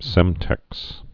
(sĕmtĕks)